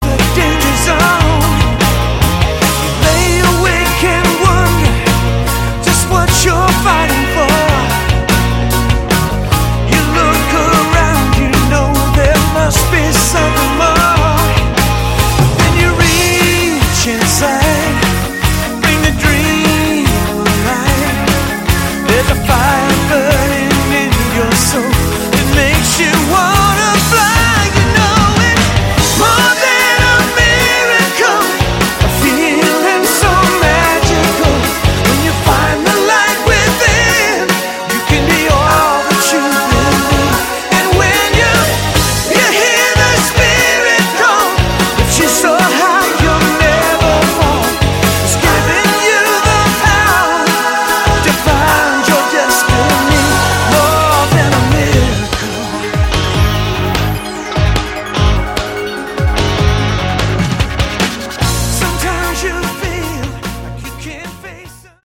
Category: AOR
vocals
guitars, keyboards
bass
drums